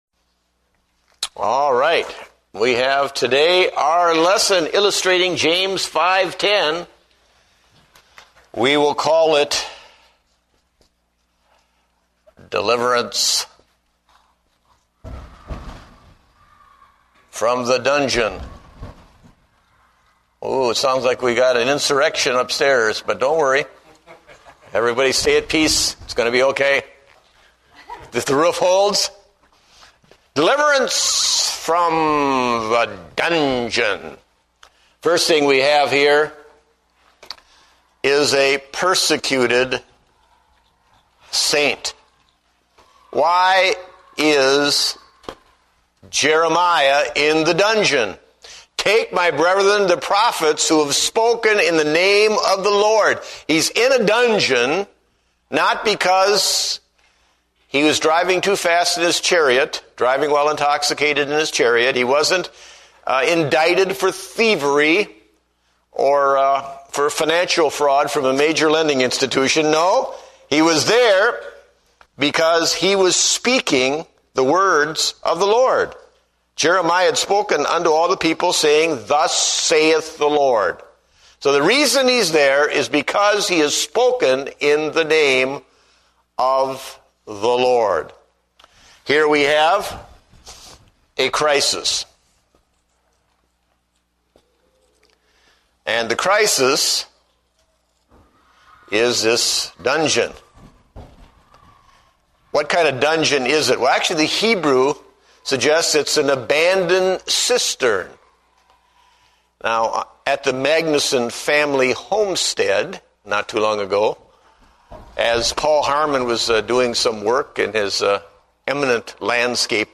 Date: September 28, 2008 (Adult Sunday School)